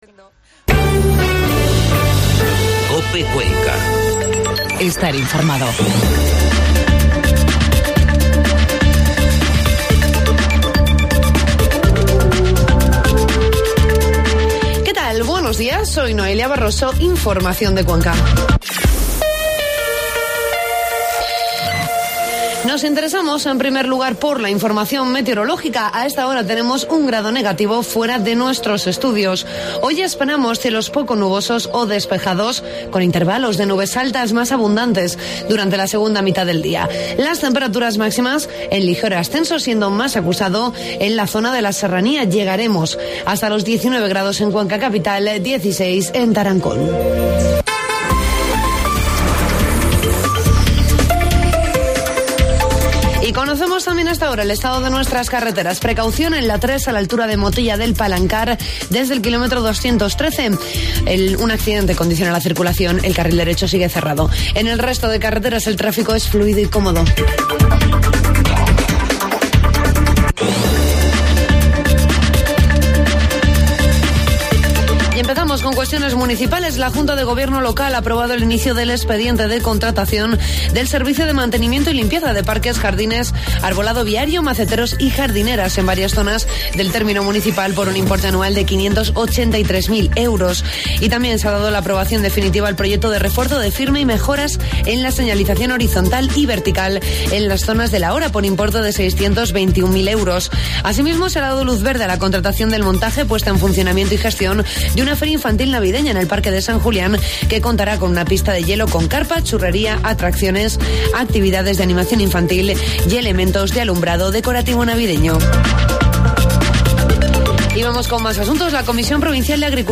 Informativo matinal COPE Cuenca 15 de noviembre